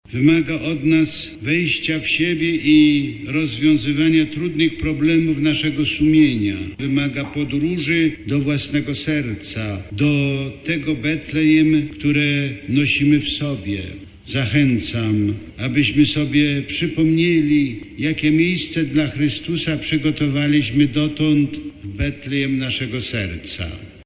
Arcybiskup Józef Kowalczyk odprawił o północy w gnieźnieńskiej katedrze Pasterkę.
epqq8g72cjpj14o_kowalczyk_pasterka.mp3